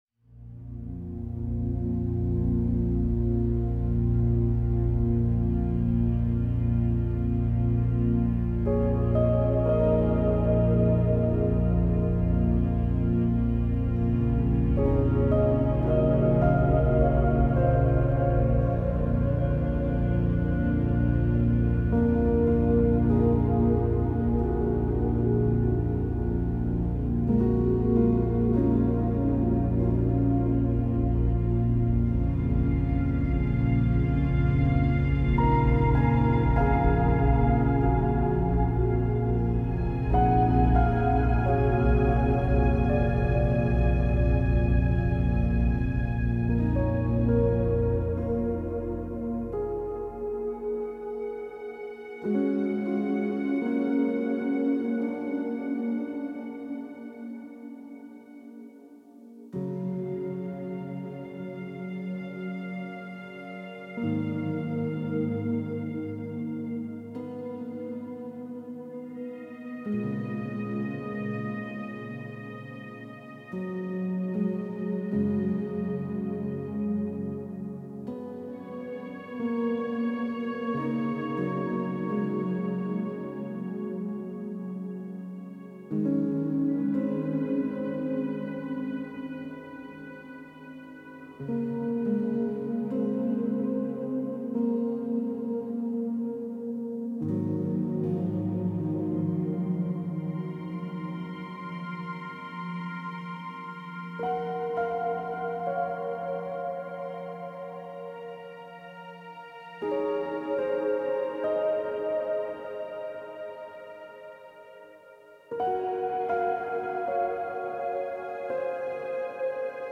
01 - Ambience